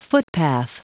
Prononciation du mot footpath en anglais (fichier audio)
Prononciation du mot : footpath